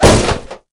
crystaldrop.ogg